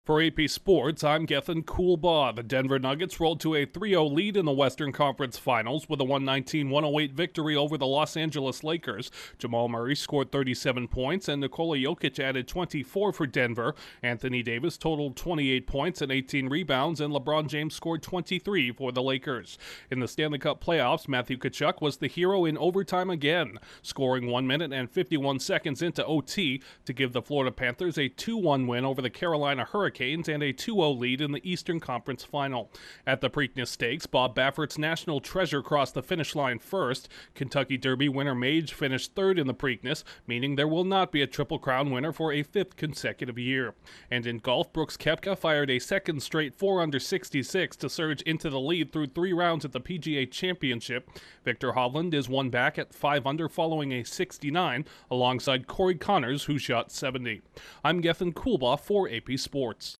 AP News Summary at 1:00 a.m. EDT